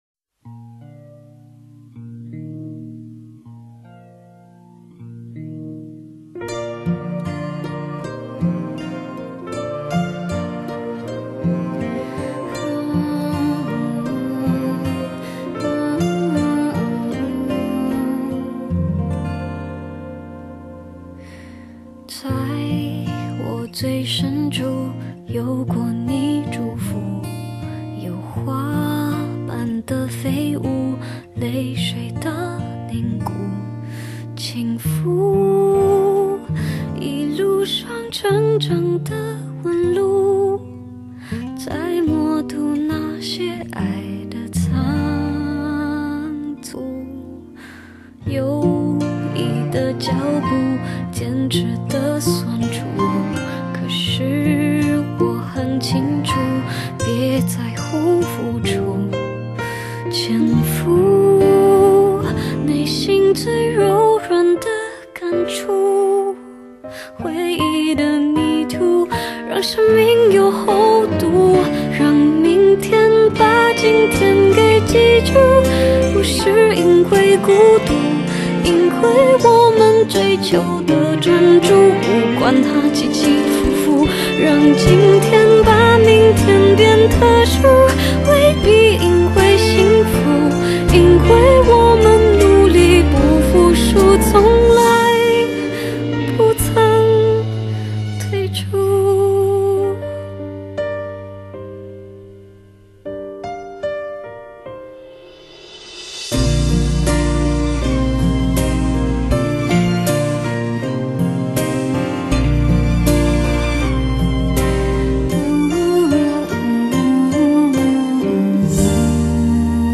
民謠風溫暖勵志曲
民謠曲風勵志好歌